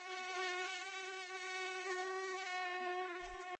cf_mosquito_buzzing.ogg